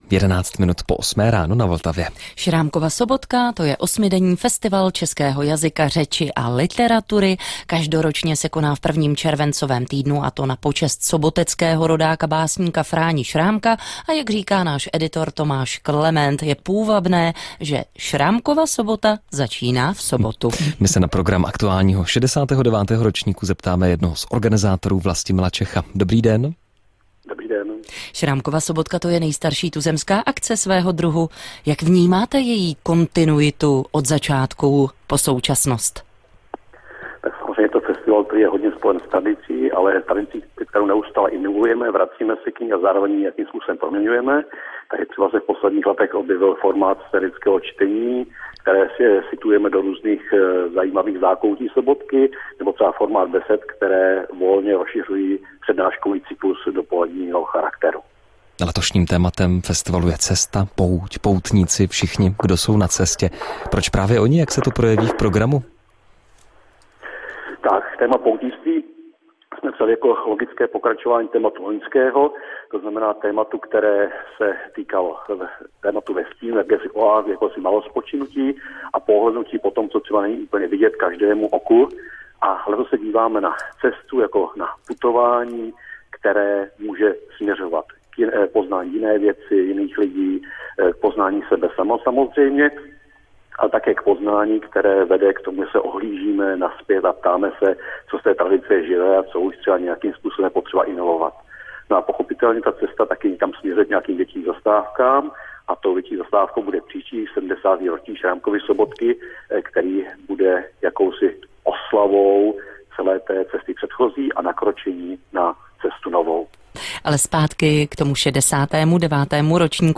rozhovor se starostou Ing.